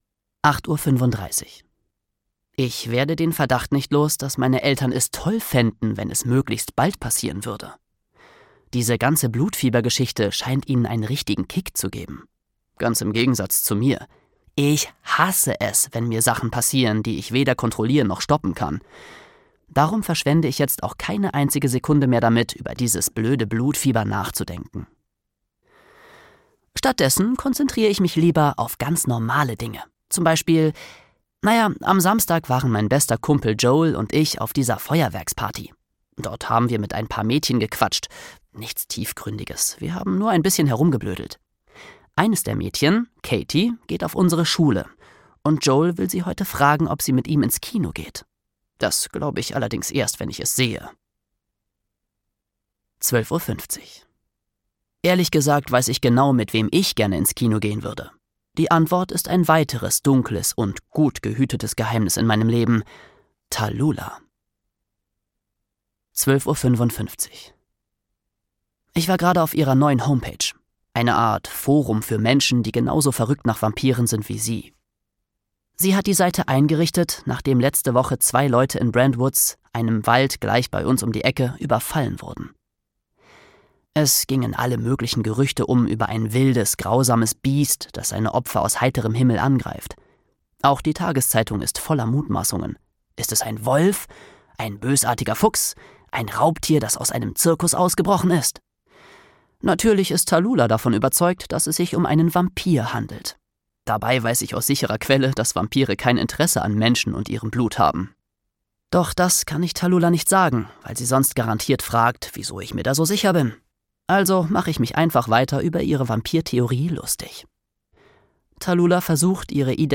Wie man 13 wird, ohne zum Tier zu werden (Wie man 13 wird 2) - Pete Johnson - Hörbuch - Legimi online